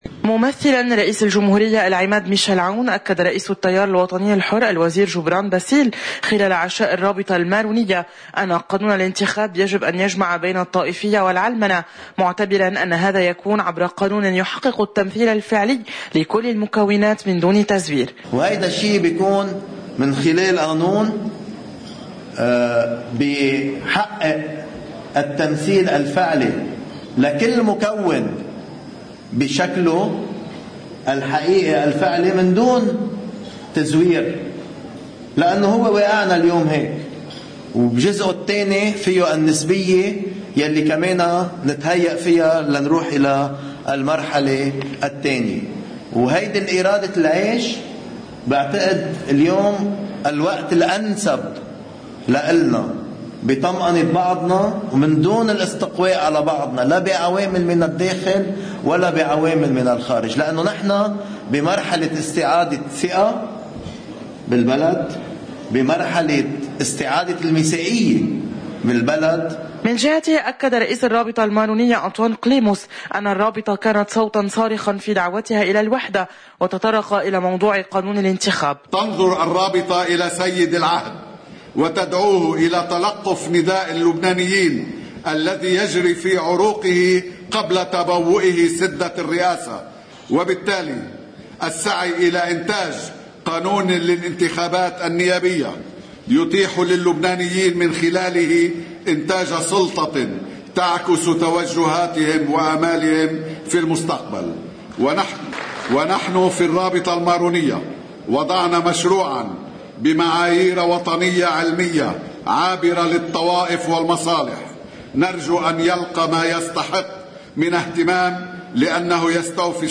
مقتطف من حديث رئيس التيار جبران باسيل في حفل عشاء الرابطة المارونية (ممثلاً الرئيس عون):